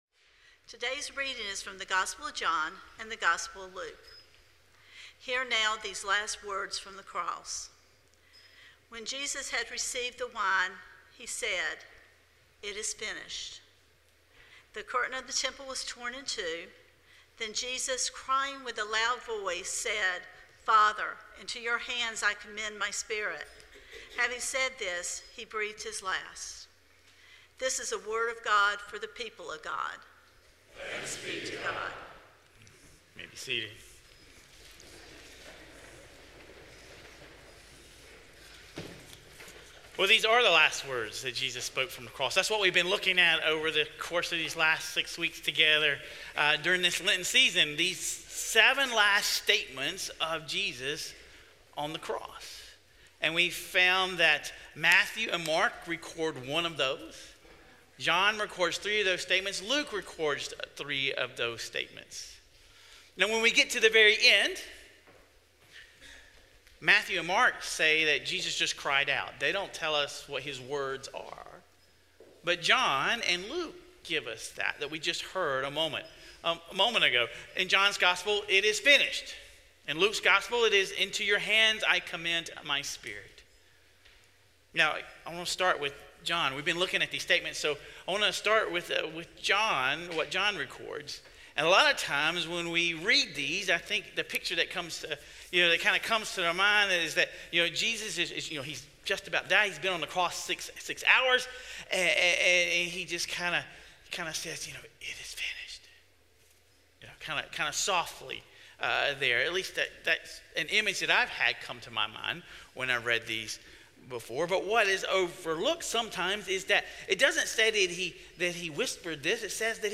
This Palm Sunday sermon examines how these statements weren't whispered in defeat but cried out in victory.